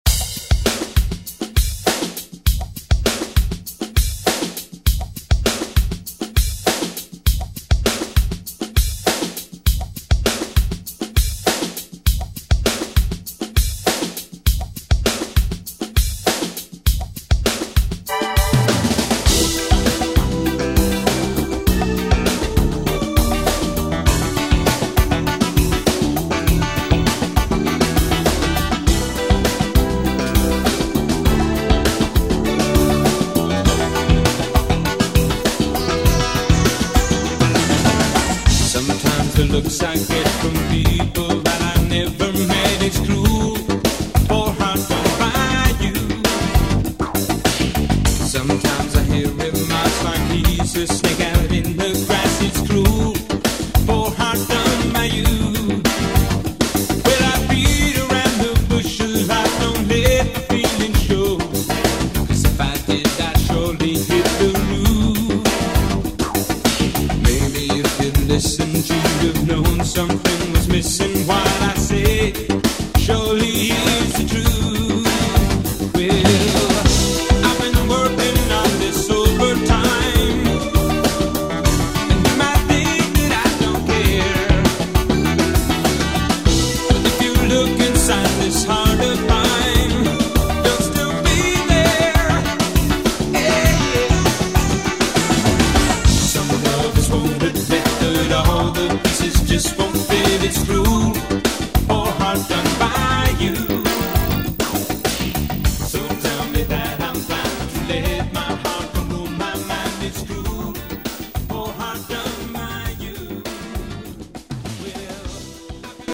Electronic Pop Music
117 bpm
Genres: 80's , RE-DRUM